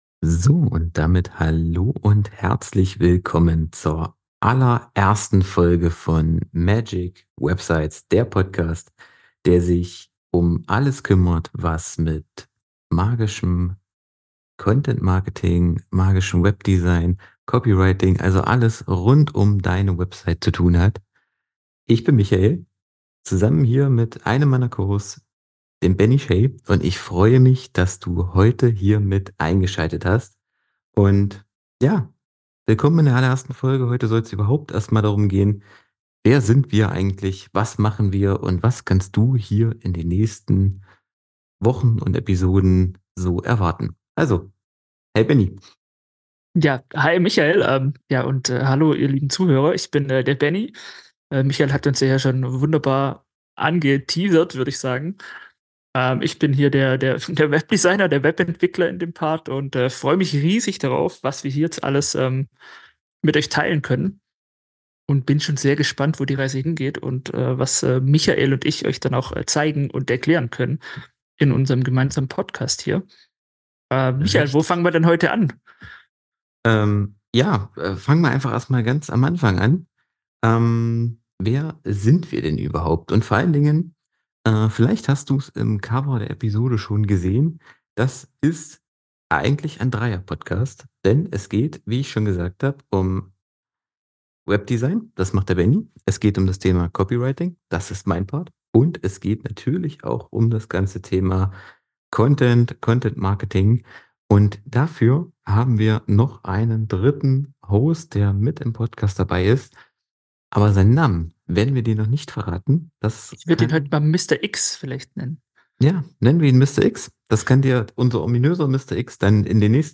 Jeder der drei Hosts steht für einen eigenen Bereich und eine individuelle Perspektive auf das Thema Webseiten.